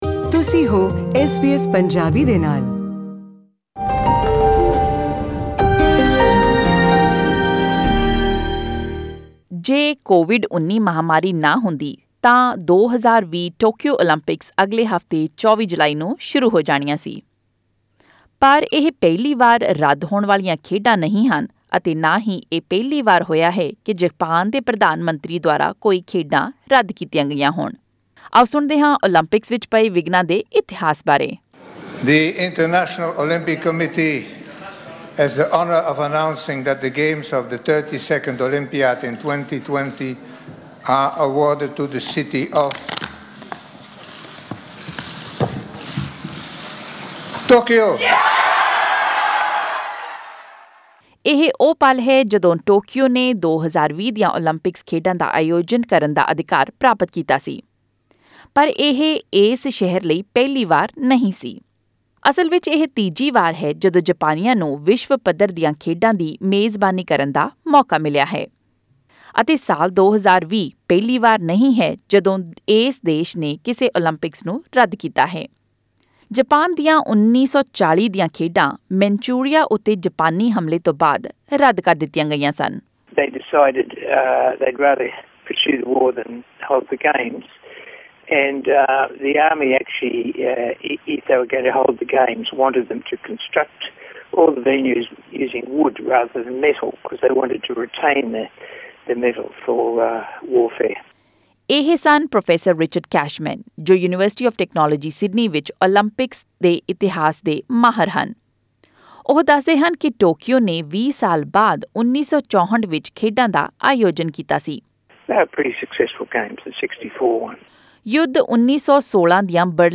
To hear the full report click on the audio link above.